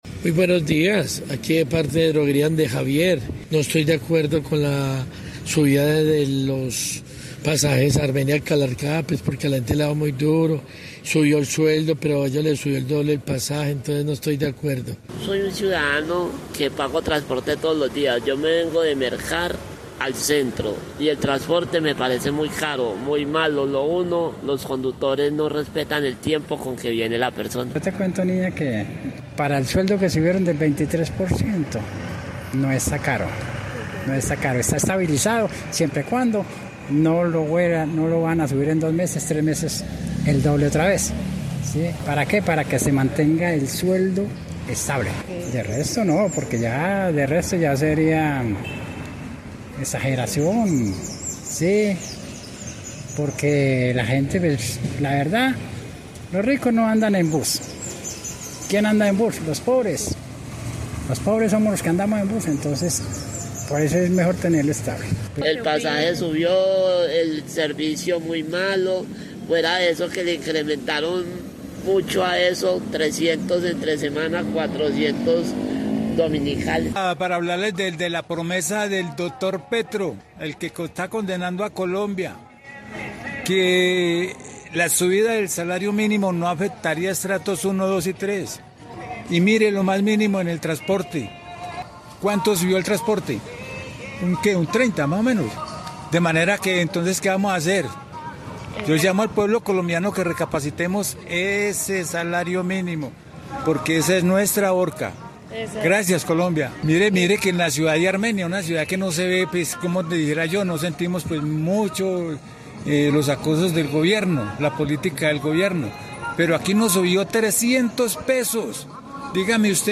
Ante esta situación, Caracol Radio Armenia salió a las calles de la capital quindiana para conocer la opinión de los usuarios frente al aumento en el valor del pasaje del transporte público, una medida que impacta de manera directa la movilidad diaria de trabajadores, estudiantes y comerciantes.